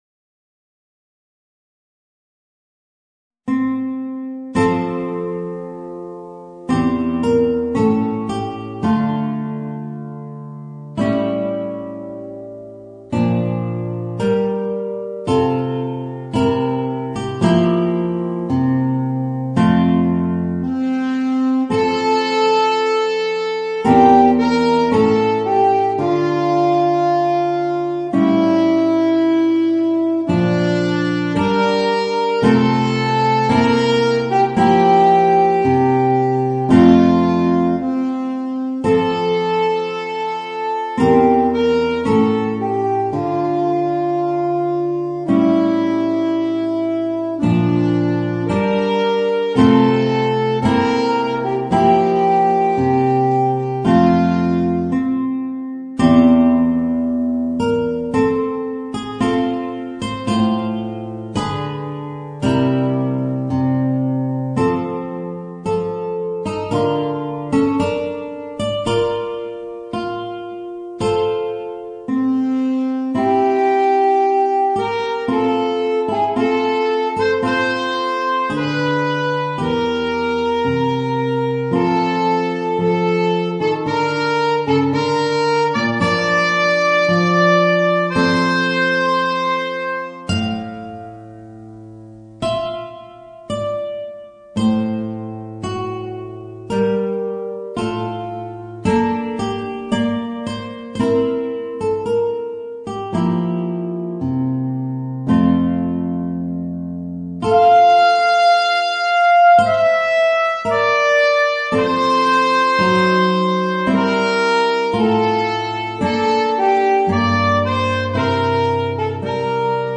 Voicing: Guitar and Alto Saxophone